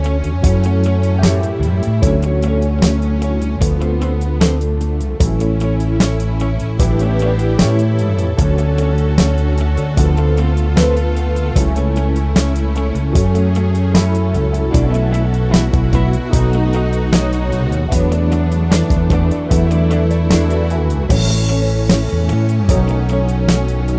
no Backing Vocals Disco 4:55 Buy £1.50